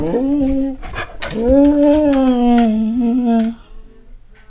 描述：我的狗要求散步
Tag: 搞笑 说话